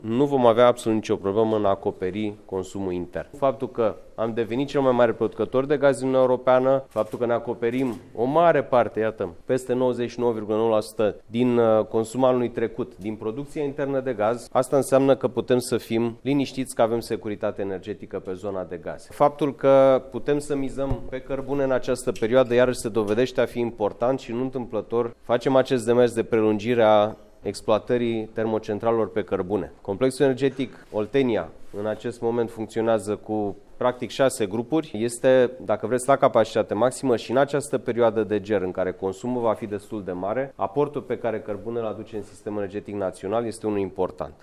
El a participat la Comandamentul de iarnă în care a fost evaluată starea sistemului energetic naţional: